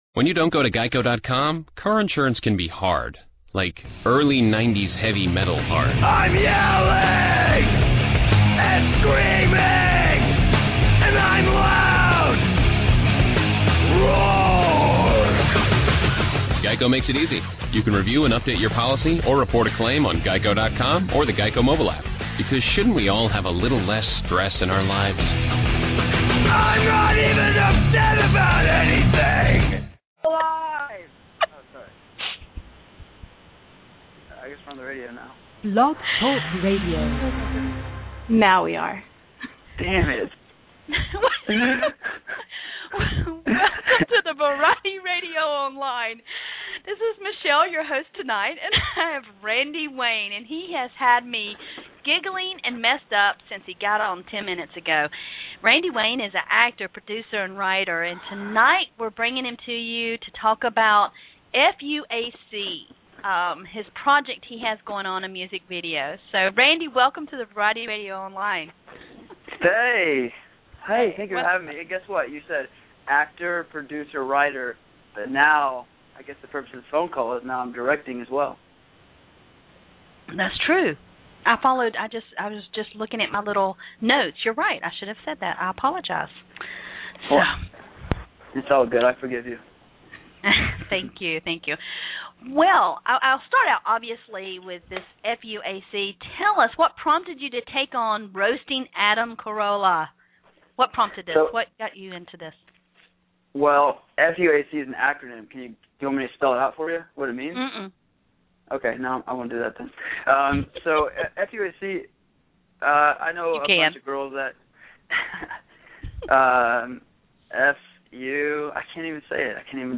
Randy Wayne 2013 Interview